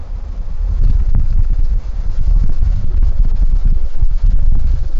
A soft, steady breeze rustling through open air with a calming presence
gentle-breeze.mp3